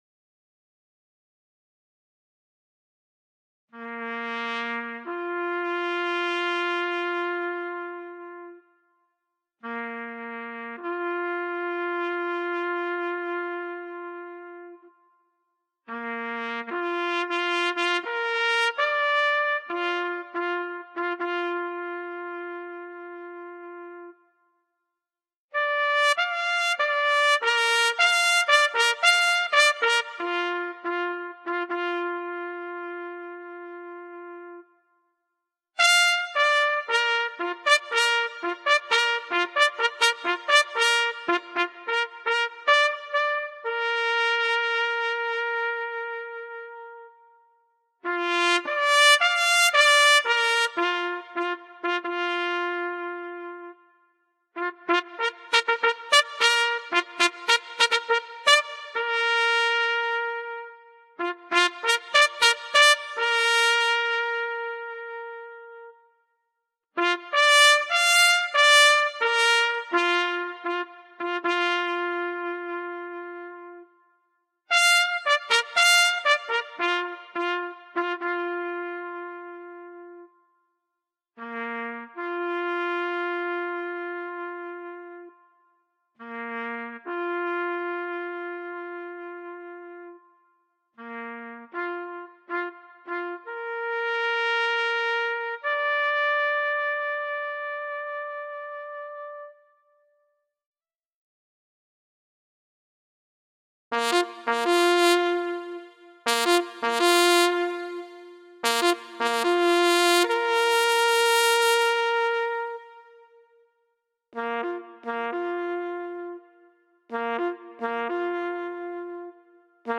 The-Last-Post-and-Rouse-EWI-Demo.mp3